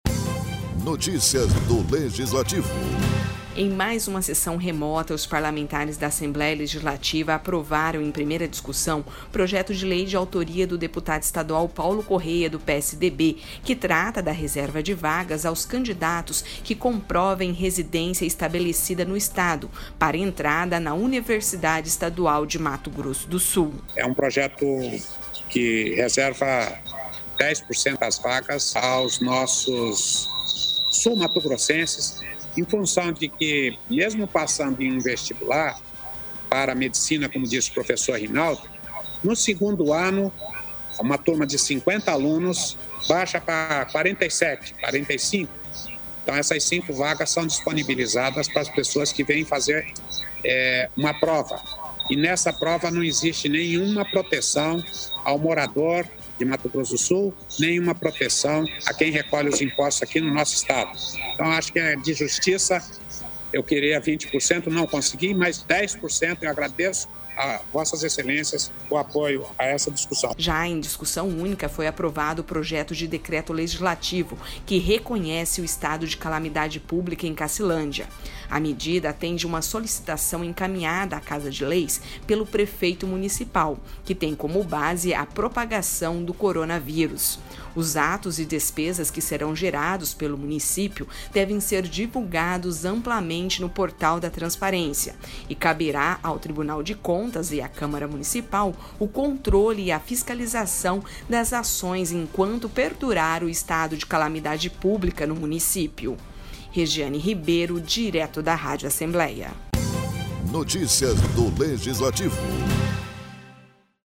Na sessão ordinária desta quinta-feira (21), os parlamentares da Assembleia Legislativa aprovaram em primeira discussão, o Projeto de Lei de autoria do presidente da Casa de Leis, o deputado estadual Paulo Corrêa (PSDB), que trata da Reserva de Vagas aos candidatos que comprovem residência estabelecida no Estado para entrada na Universidade Estadual de Mato Grosso do Sul (UEMS).